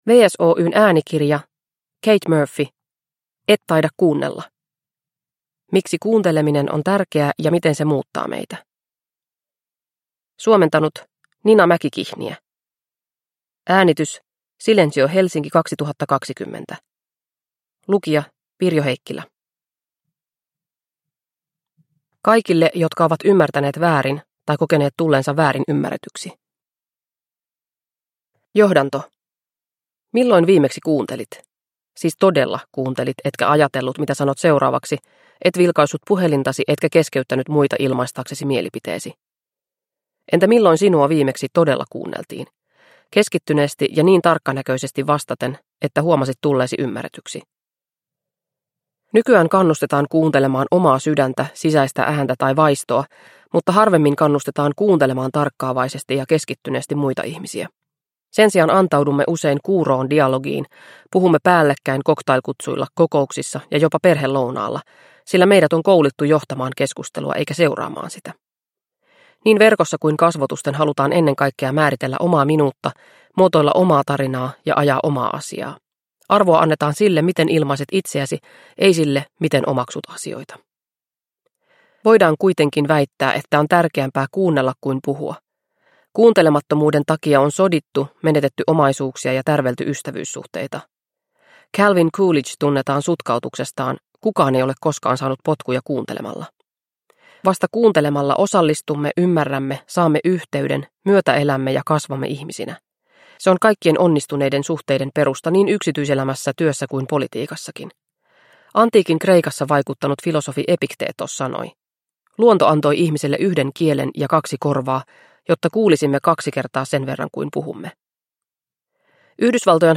Et taida kuunnella – Ljudbok – Laddas ner